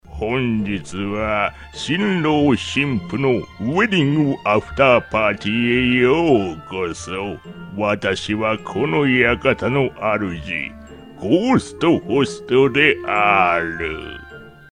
日语老年低沉 、大气浑厚磁性 、沉稳 、素人 、男专题片 、宣传片 、纪录片 、广告 、绘本故事 、动漫动画游戏影视 、微电影旁白/内心独白 、150元/百单词男日102T-4 日语 老人 角色电影 低沉|大气浑厚磁性|沉稳|素人
男日102T-4 日语 全能声音多变老人DJ电影旁白广告 低沉|大气浑厚磁性|沉稳|娓娓道来|科技感|积极向上|时尚活力|神秘性感|调性走心|亲切甜美|感人煽情|素人